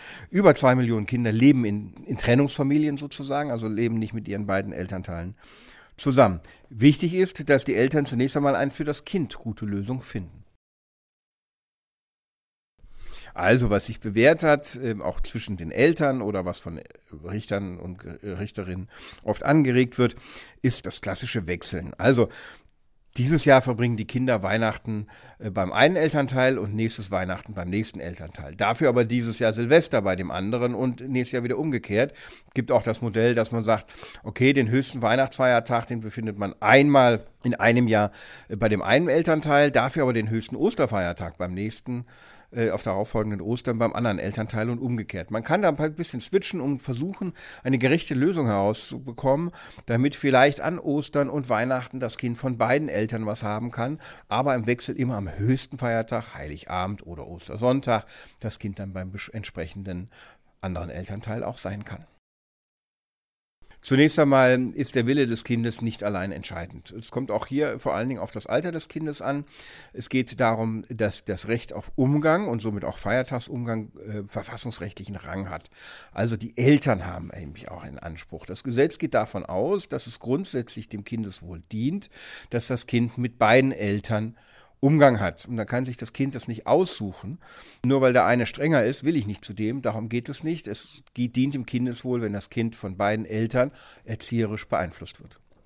Kollegengespräch: Wo verbringen Trennungskinder die Feiertage?